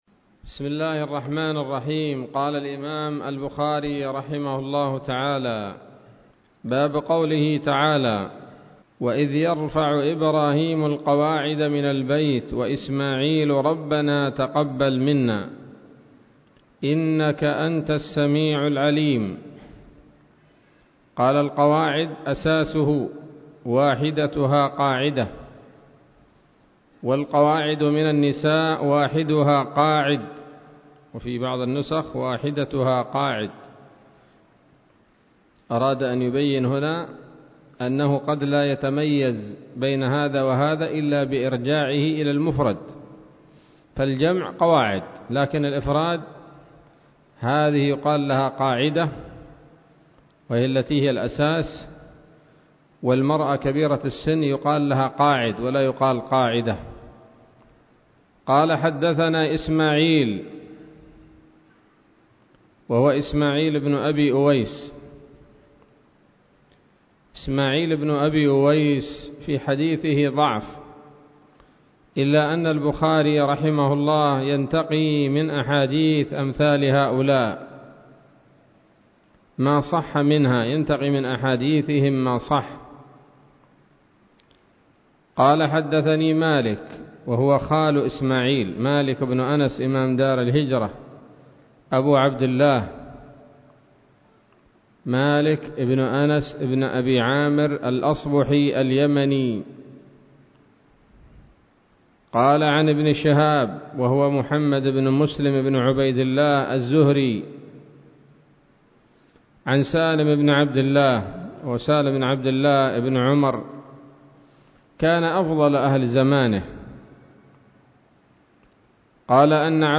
الدرس الحادي عشر من كتاب التفسير من صحيح الإمام البخاري